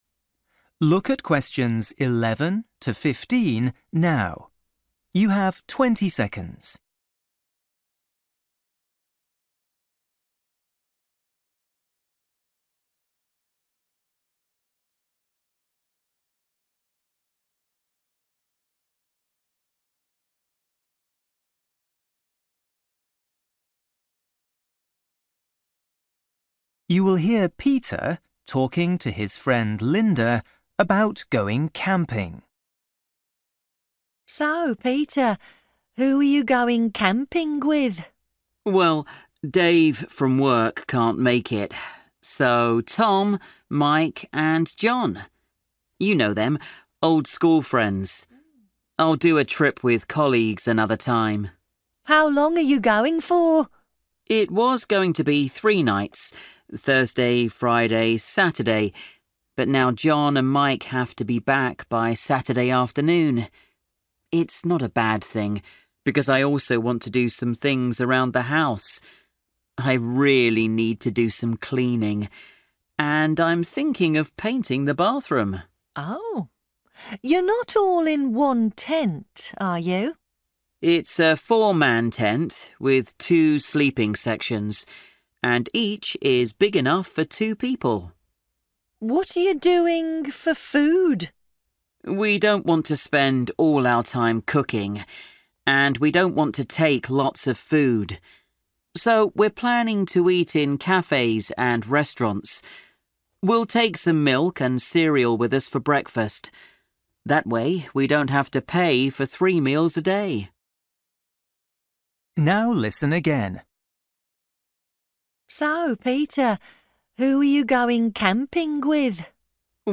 20   You will hear a woman talking to her friend.